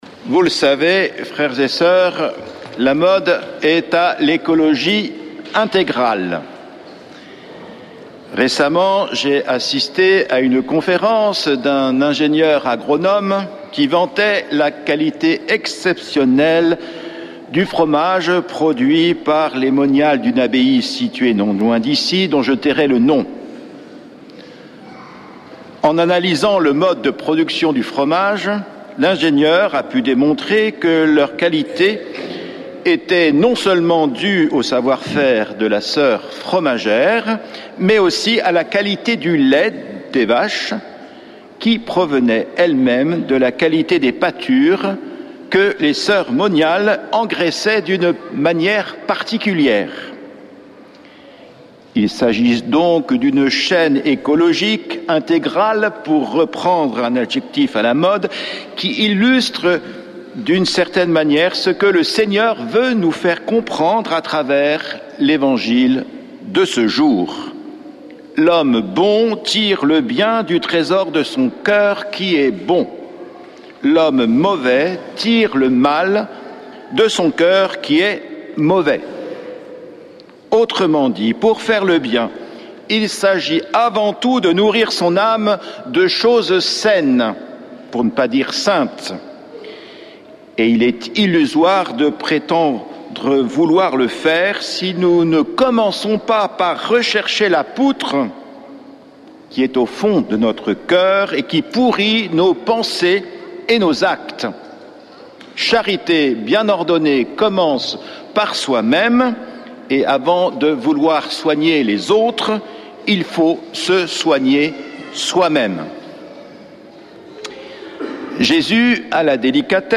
Accueil \ Emissions \ Foi \ Prière et Célébration \ Messe depuis le couvent des Dominicains de Toulouse \ Seigneur, donne-moi les yeux de ta miséricorde !